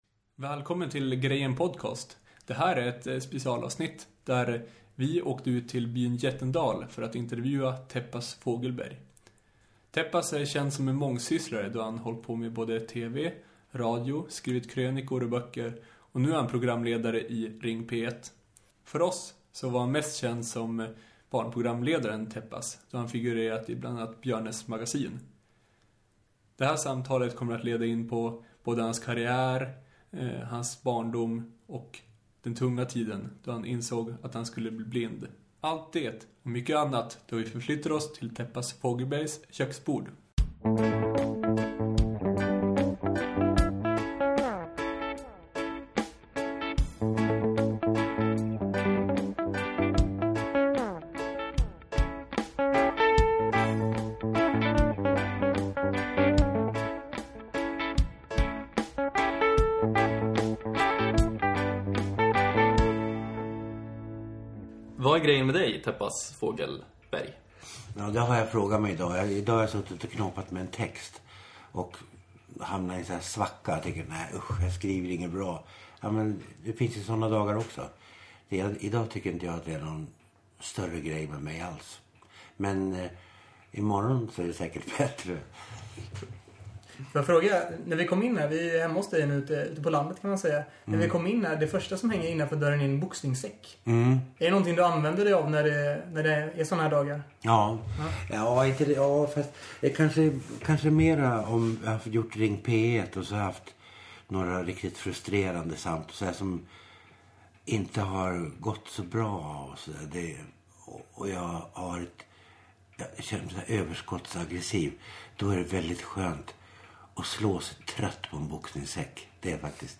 Vi träffar journalistprofilen Täppas Fogelberg och samtalar om hans liv och karriär. Lyssna på ett djupt och intressant samtal runt hans köksbord.